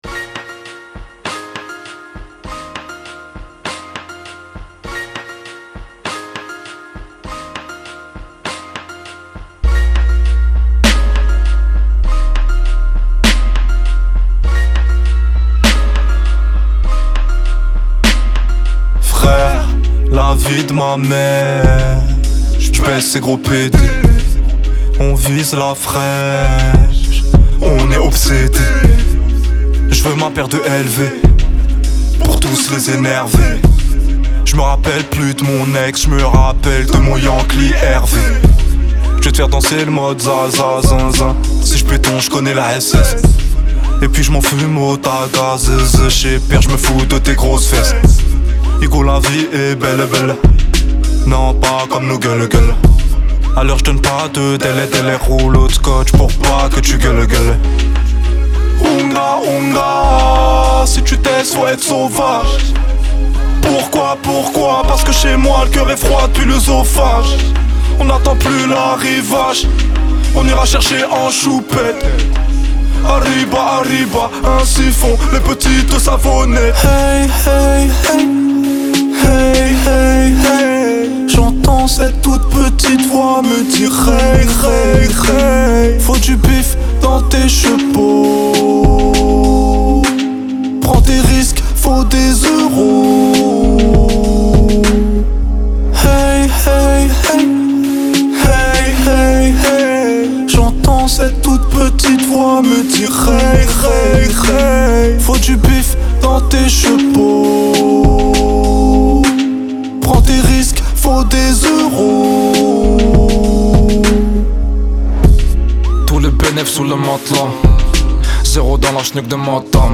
Genres : french rap